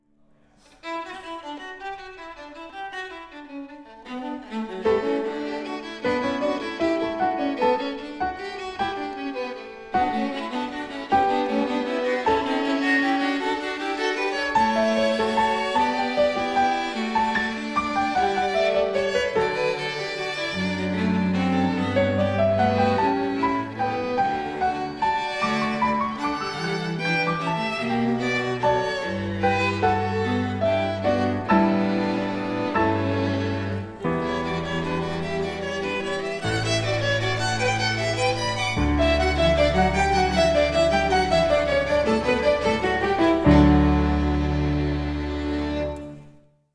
Recessional wedding music for piano quintet